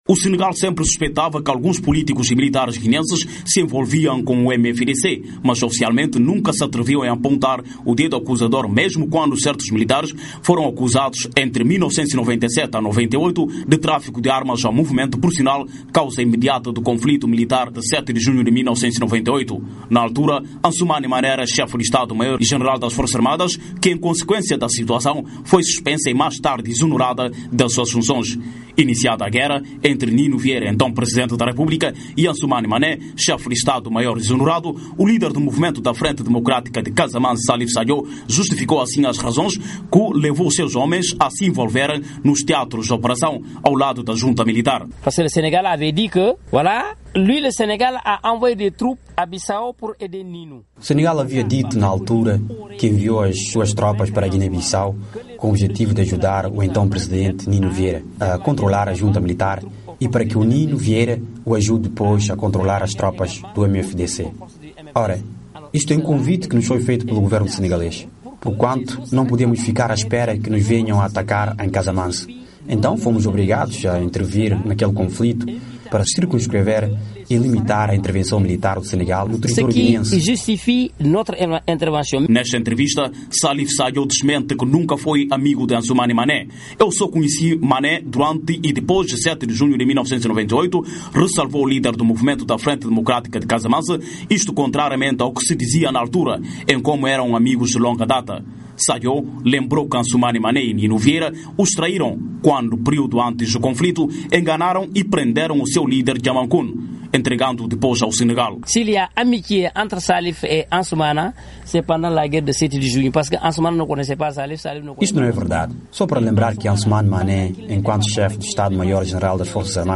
Em entrevista à VOA Salif Sadio fala das relações por vezes conturbadas com Bissau